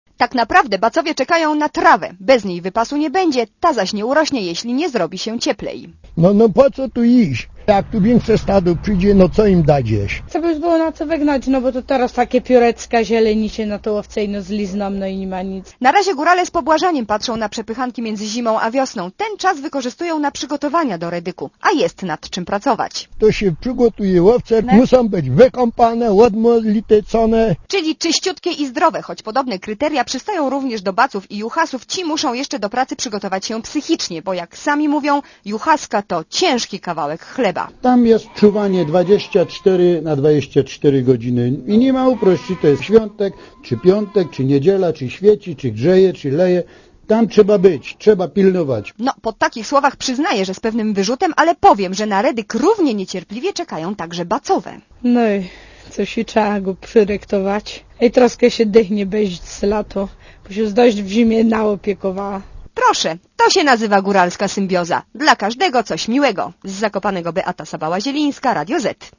Posłuchaj relacji reporterki Radia ZET (268 KB)
owce-redyk.mp3